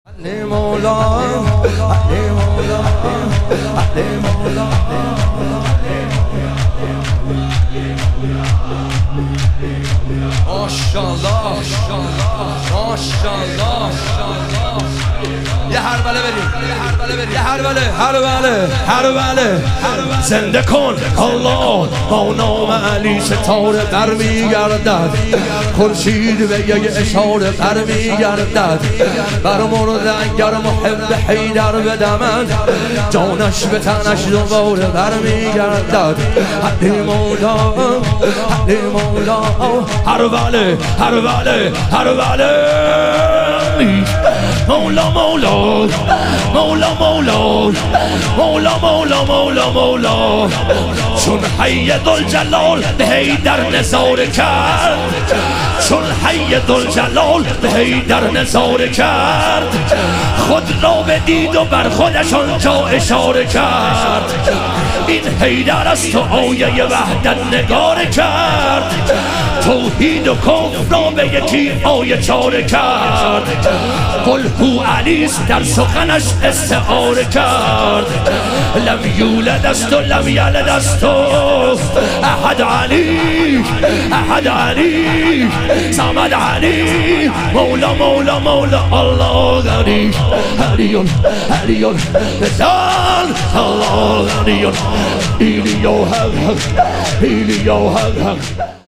ایام فاطمیه دوم - شور